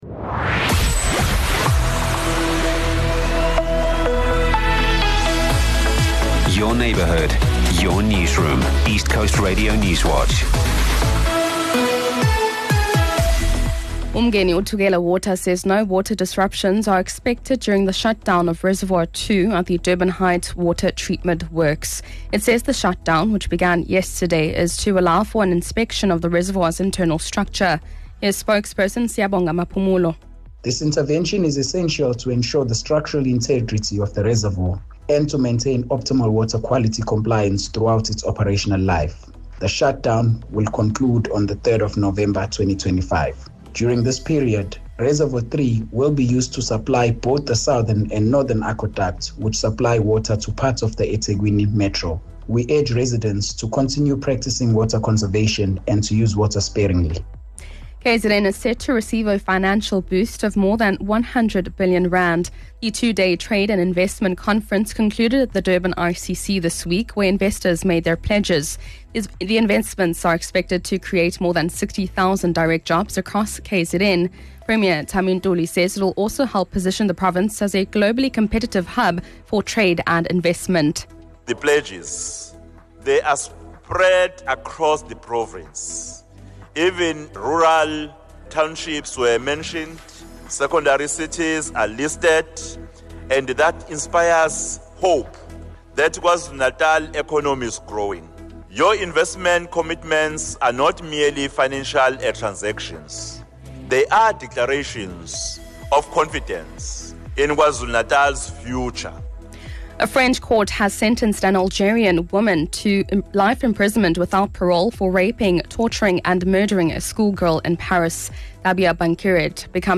Here’s your latest ECR Newswatch bulletin from the team at East Coast Radio.